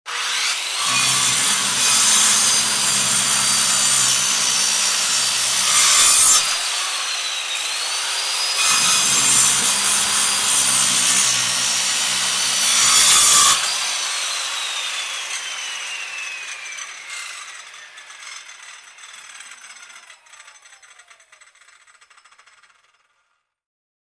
in_circularsaw_cut_03_hpx
Circular saw cuts wood as blade spins. Tools, Hand Wood, Sawing Saw, Circular